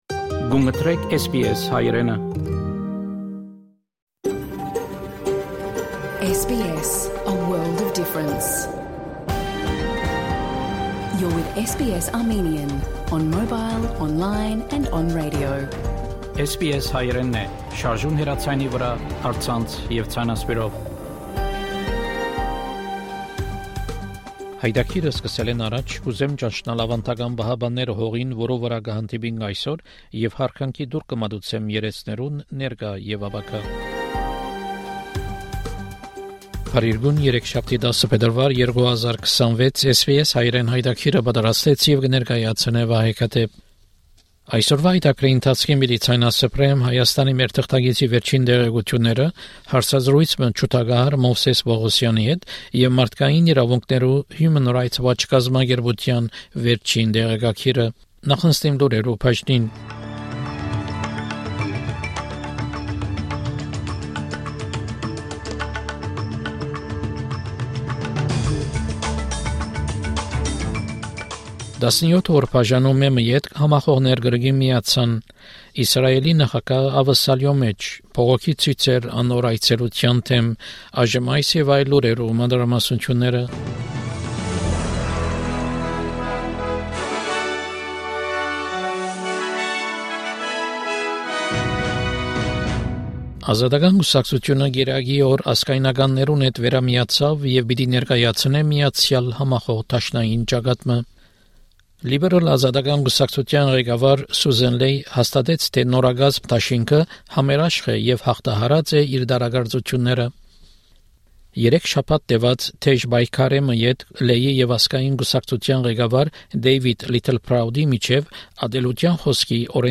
SBS Armenian news bulletin from 10 February 2026 program.